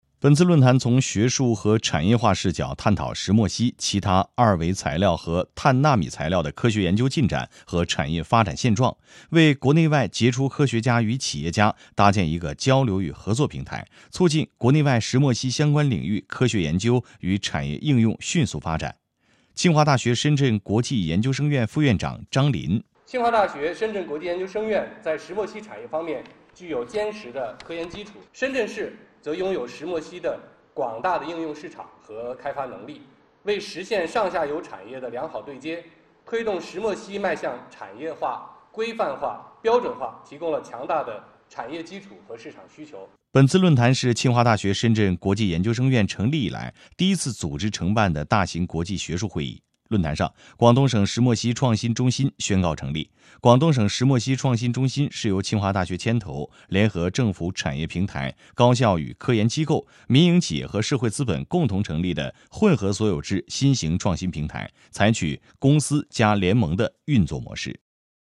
中央人民广播电台播出 深圳国际石墨烯论坛举行.mp3